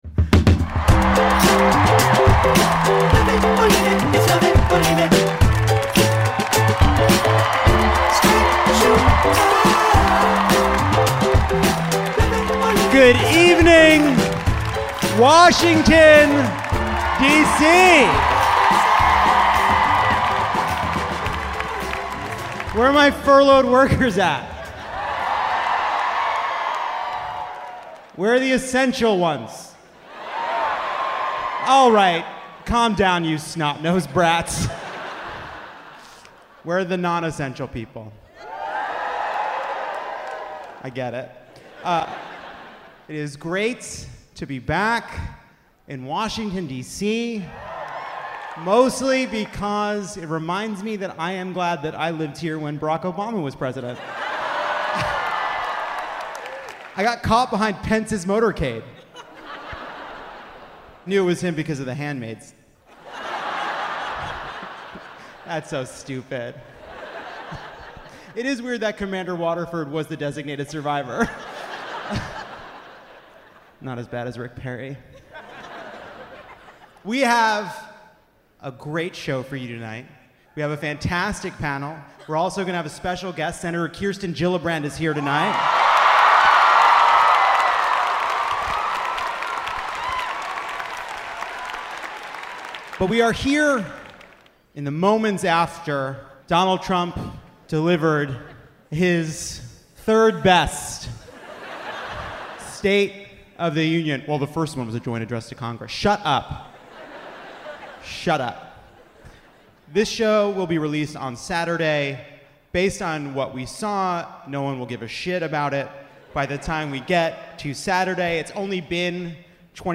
And Senator Kirsten Gillibrand joins Jon on stage at the Anthem in Washington, DC to discuss her 2020 campaign, health care, and the ugly scandals unfolding in Virginia.
Alex Wagner, Alyssa Mastromonaco, and Akilah Hughes are here to help break down the week's news and rant about Marie Kondo and The Bachelor.